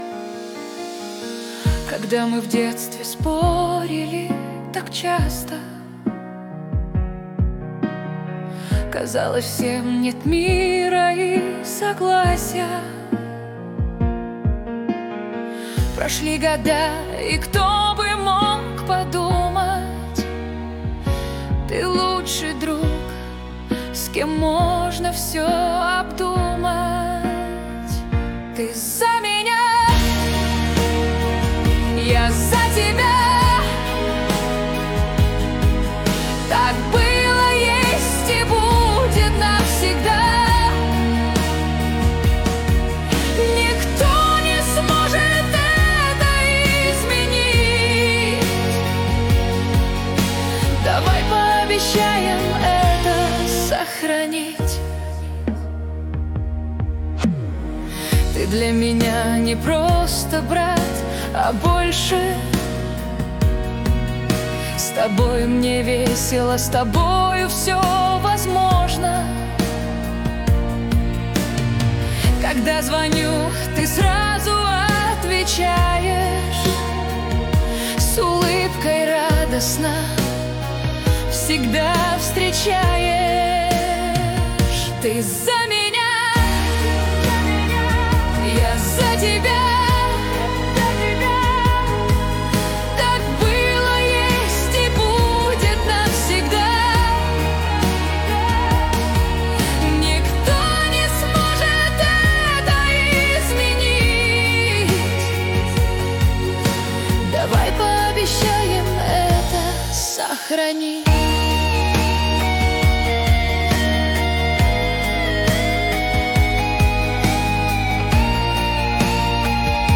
Нейросеть (ИИ) -